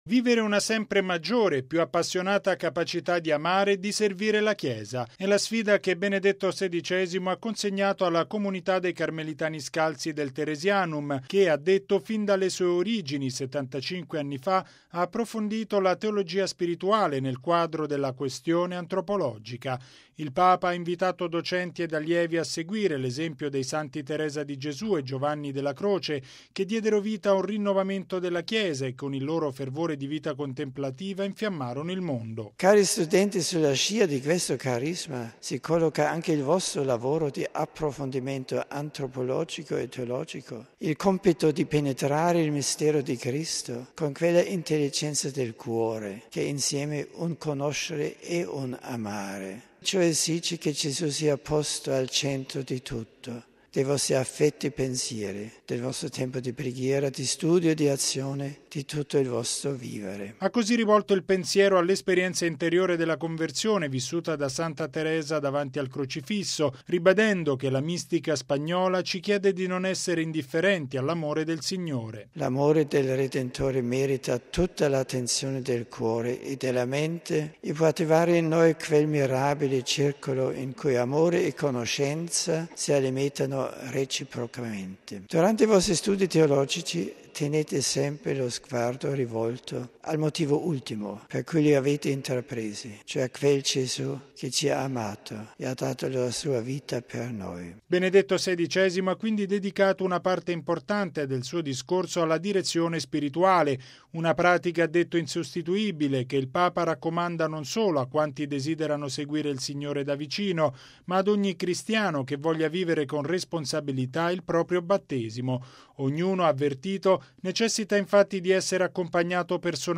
“Vivere una sempre maggiore e più appassionata capacità di amare e di servire la Chiesa”: è la sfida che Benedetto XVI ha consegnato alla comunità dei Carmelitani scalzi del Teresianum, che, ha detto, fin dalle sue origini, 75 anni fa, ha approfondito la teologia spirituale nel quadro della questione antropologica.